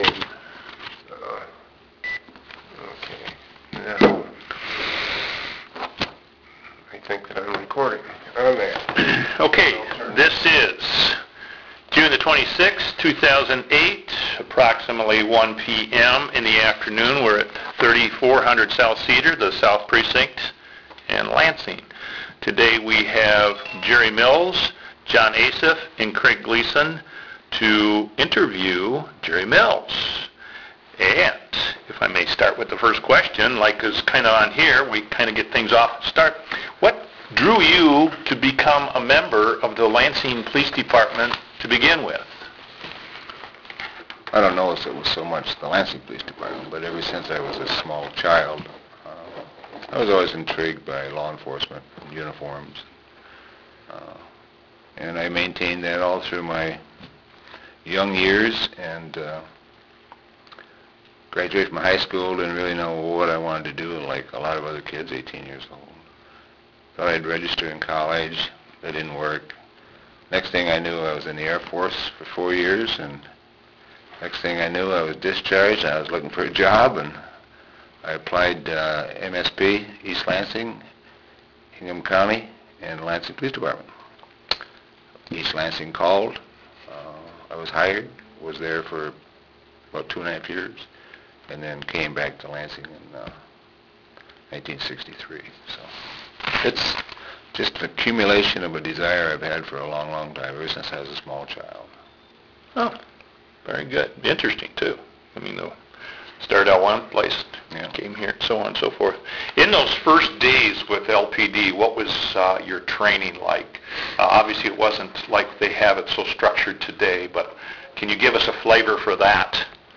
Interviews of retired Lansing Police Chief Jerry Mills on his career and experiences
Oral History Project